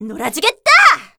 assassin_w_voc_cripplepunisher01.ogg